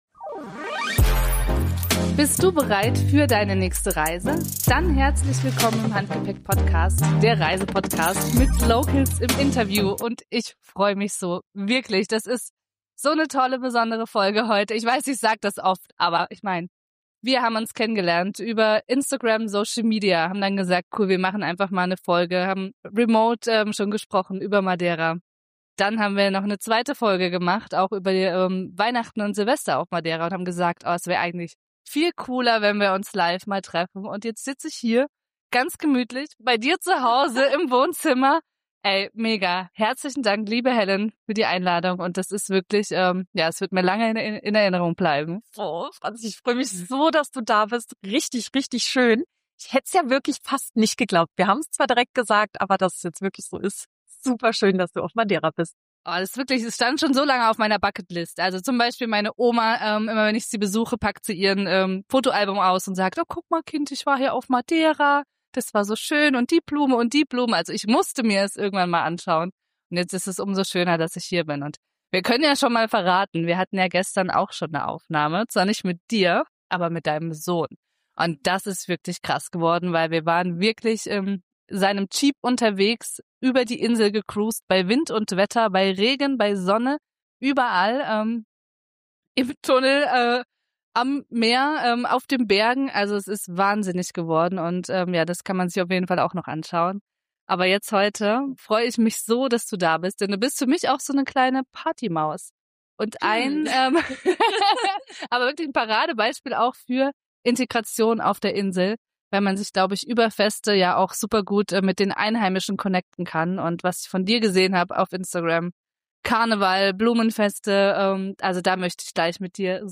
In dieser Folge nehmen wir dich direkt mit auf die Insel – aufgenommen vor Ort!